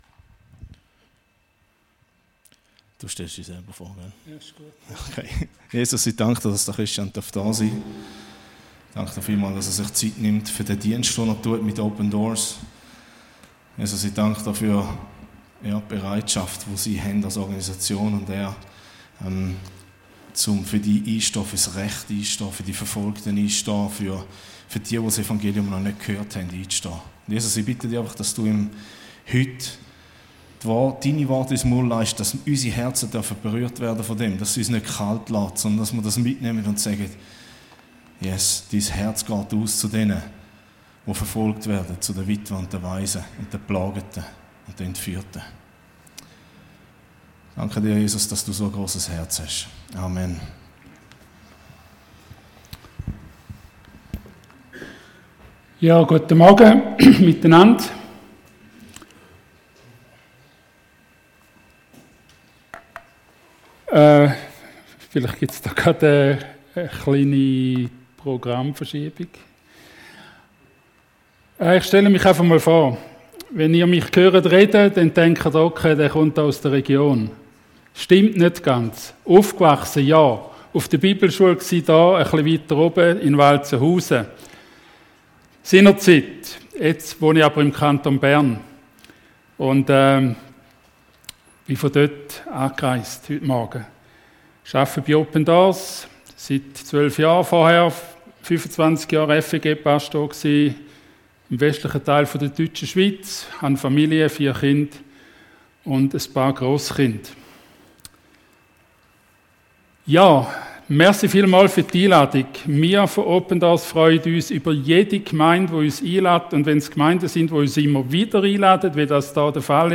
Open Doors ~ Predigten D13 Podcast
Hier hörst du die Predigten aus unserer Gemeinde.